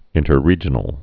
(ĭntər-rējə-nəl)